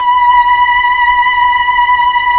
Index of /90_sSampleCDs/Keyboards of The 60's and 70's - CD1/STR_Melo.Violins/STR_Tron Violins
STR_TrnVlnB_5.wav